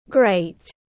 Tuyệt vời tiếng anh gọi là great, phiên âm tiếng anh đọc là /ɡreɪt/
Great /ɡreɪt/